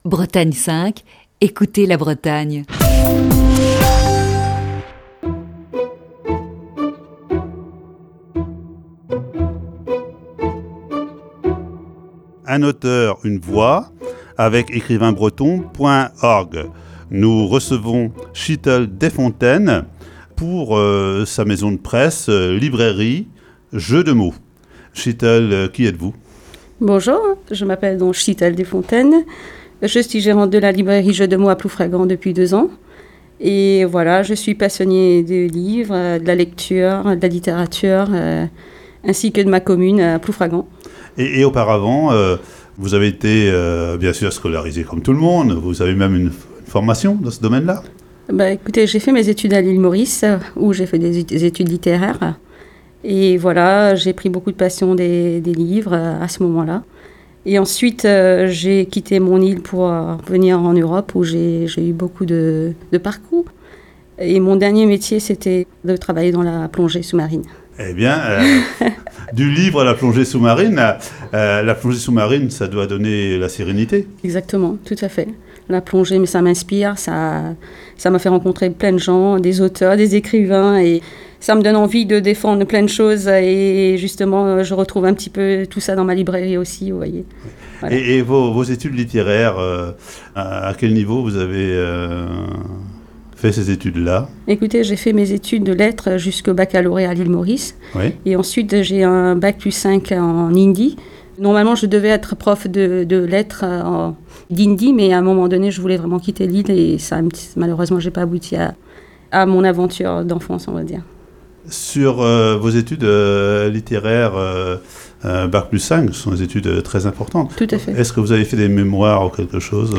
Voici, ce lundi, la première partie de cet entretien.